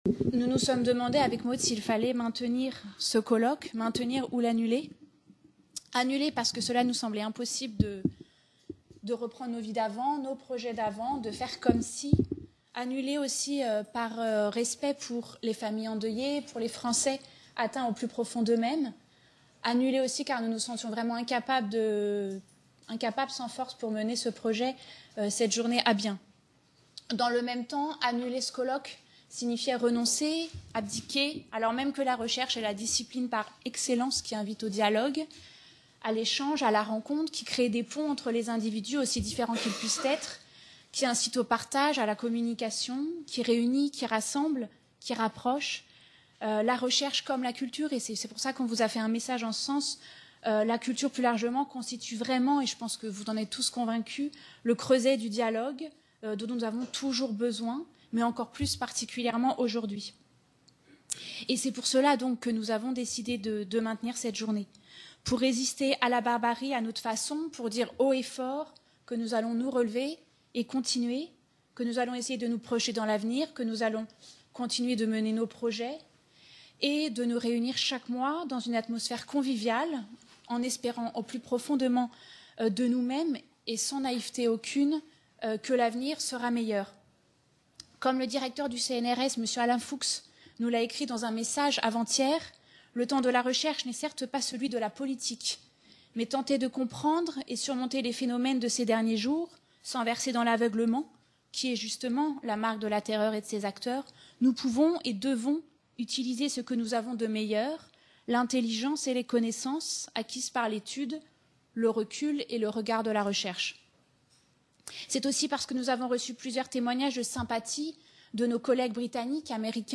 Colloque "Elle fête ses 70 ans" - 1 Introduction